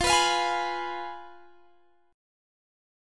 Fdim Chord
Listen to Fdim strummed